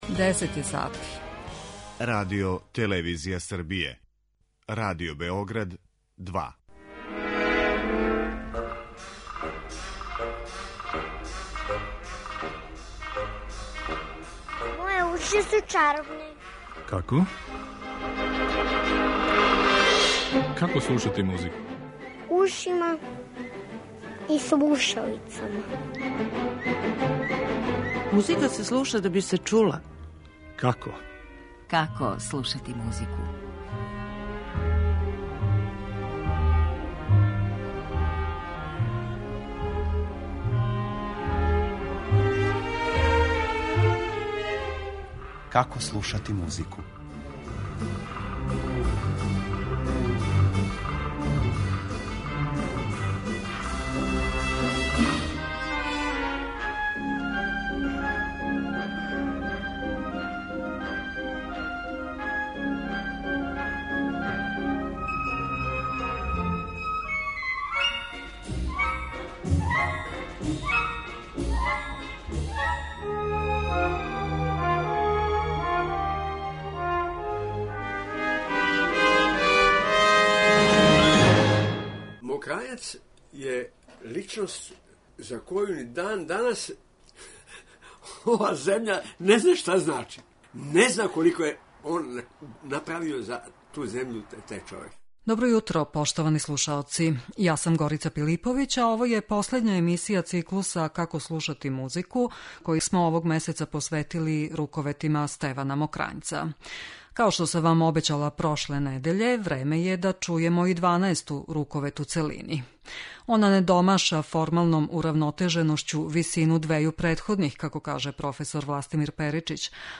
са трибине у оквиру Мокрањчевих дана у Неготину 2011. године.